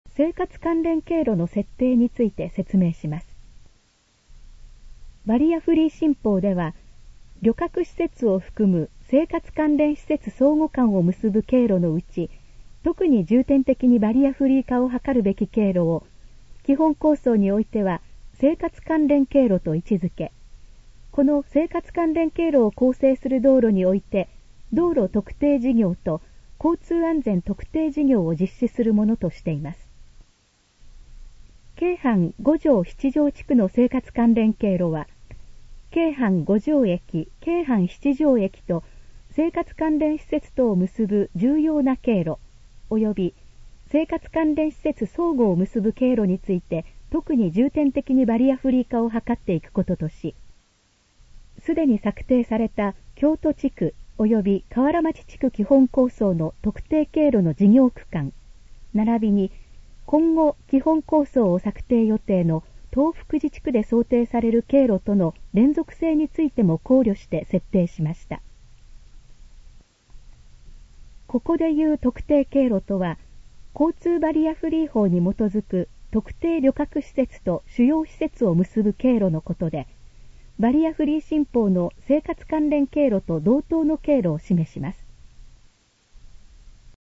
以下の項目の要約を音声で読み上げます。
ナレーション再生 約340KB